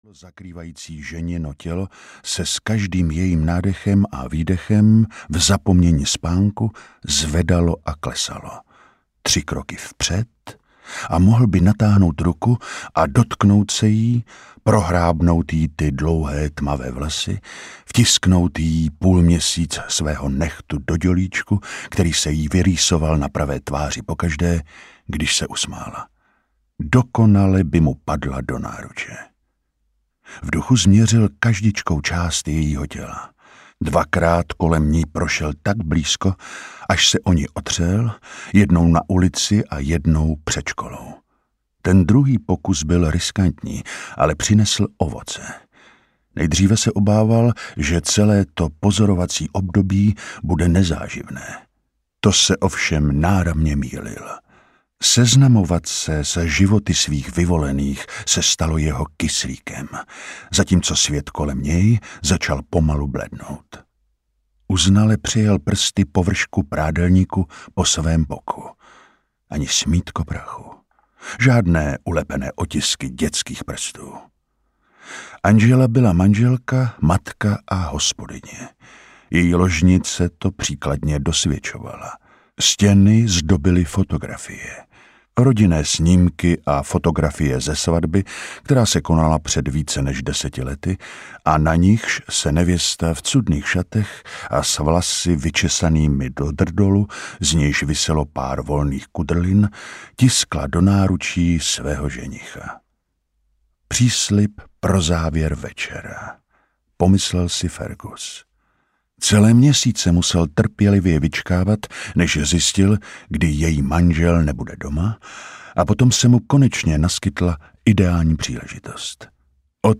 Jako stín audiokniha
Ukázka z knihy